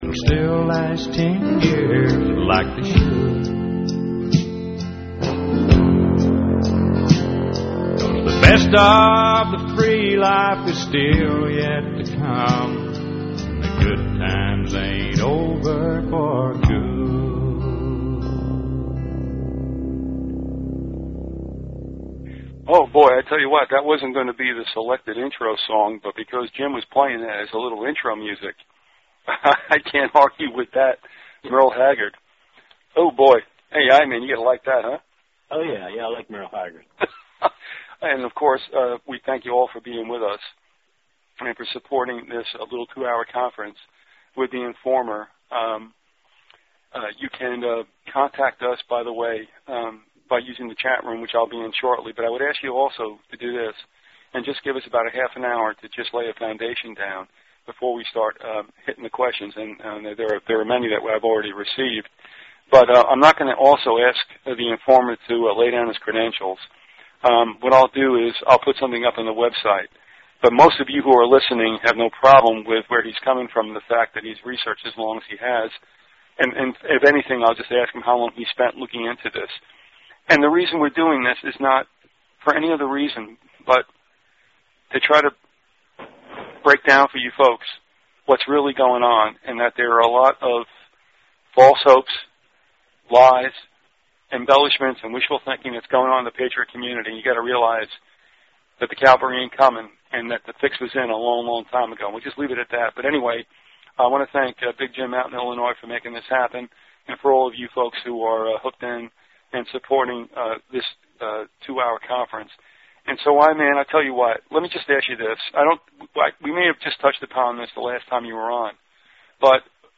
interviews
If you took notes and would like to share them with others, please send an email to the archivist and be sure to reference the title of the interview.